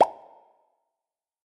Звуки мультяшных персонажей
Звук лопнувшего шарика или легкого удара в мультике